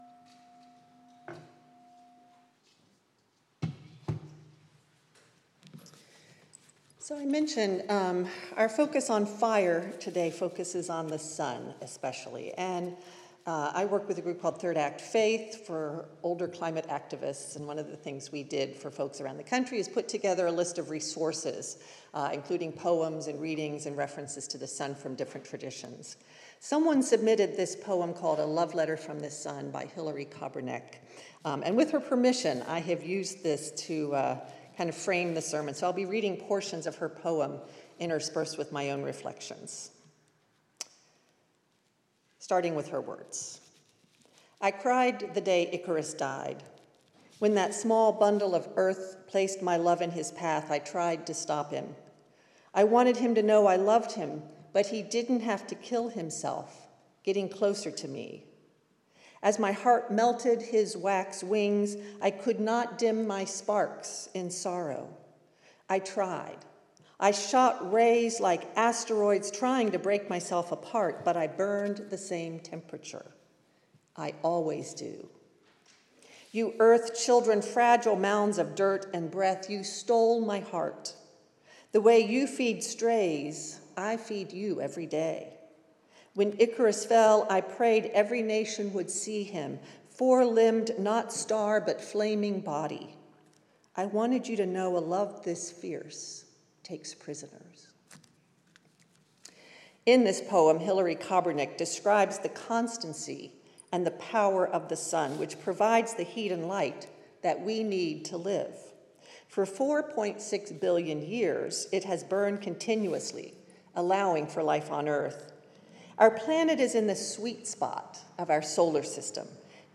Our service on fire will focus on the power of the sun and the promise that solar energy offers as we move away from burning fossil fuels. This service is part of Sun Day, a national day of action celebrating the power of clean energy.